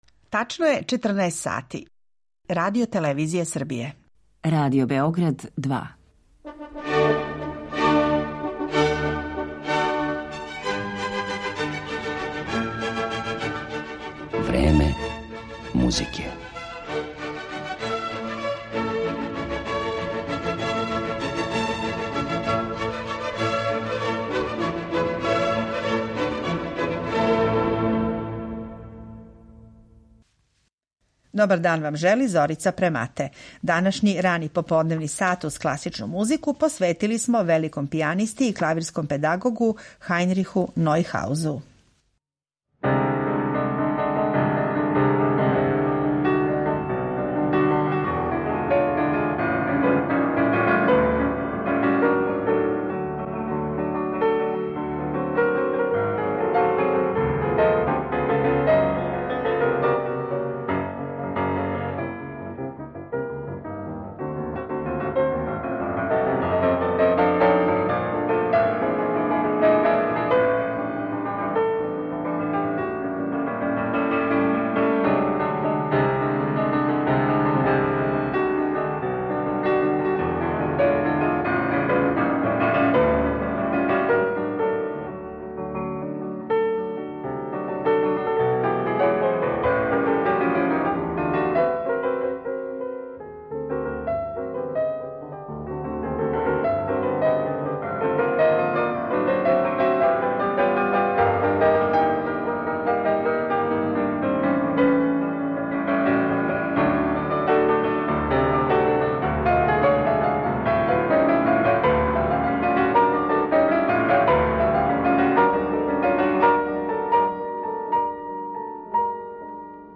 Емитоваћемо и архивске снимке на којима овај чувени професор Свјатослава Рихтера и Емила Гиљелса свира дела Скрјабина и Дебисија.